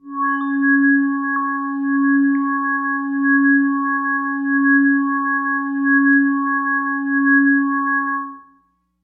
描述：通过Modular Sample从模拟合成器采样的单音。
Tag: CSharp5 MIDI音符-73 Korg的-Z1 合成器 单票据 多重采样